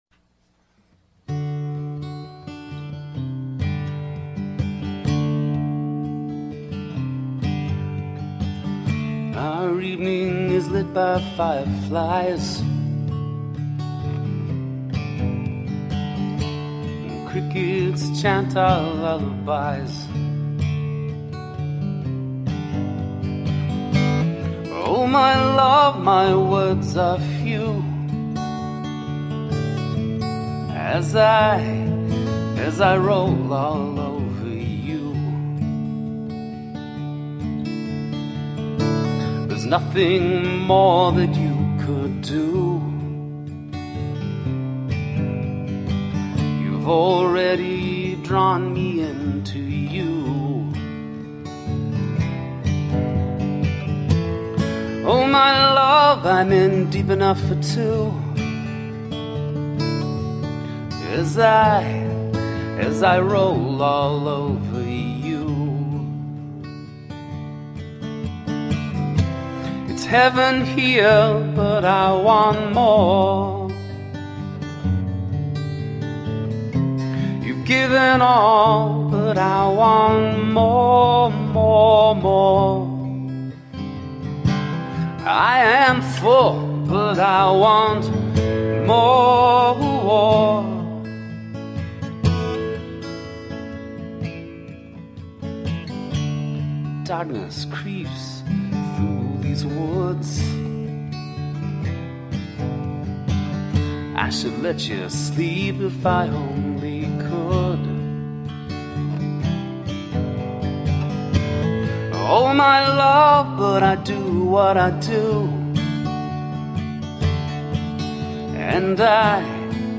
Tagged as: Alt Rock, Rock, Folk